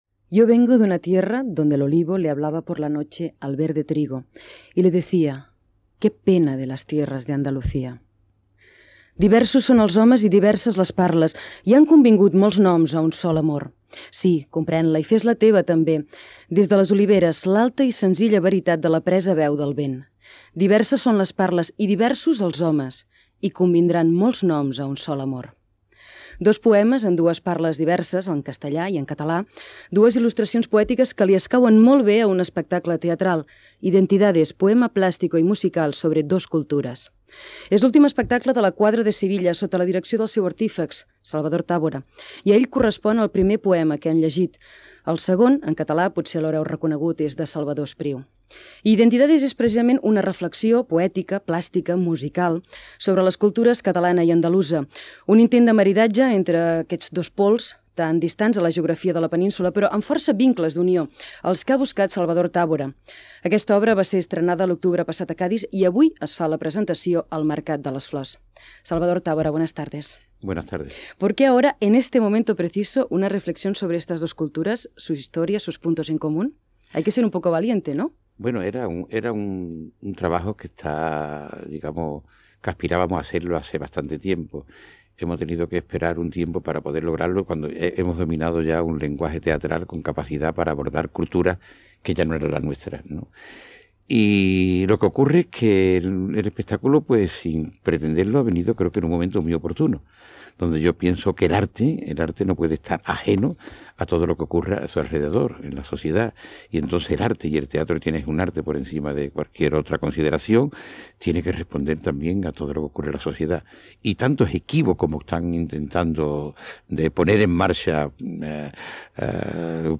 Presentació i entrevista al director teatral Salvador Távora de La Cuadra de Sevilla que presenta l'espectacle "Identidades" al Mercat de les Flors de Barcelona
Informatiu